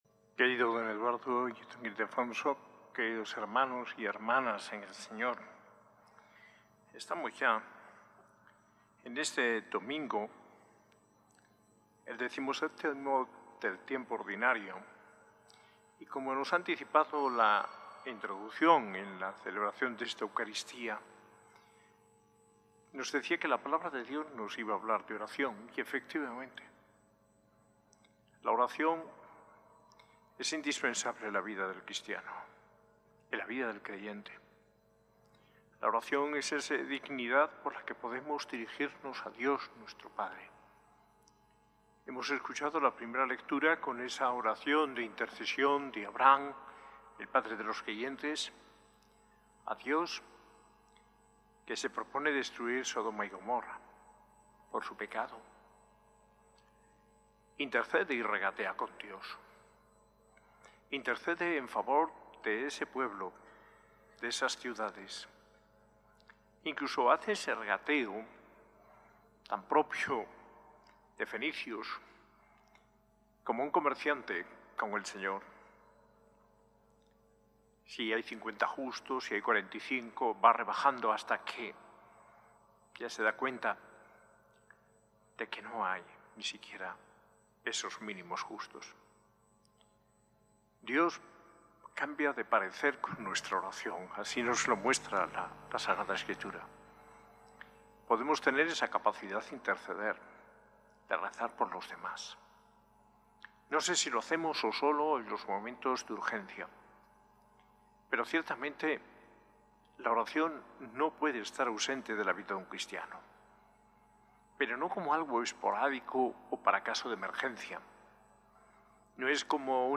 Homilía en la Eucaristía del domingo XVII del Tiempo Ordinario y jubileo de los migrantes, por el arzobispo Mons. José María Gil Tamayo, el 27 de julio de 2025.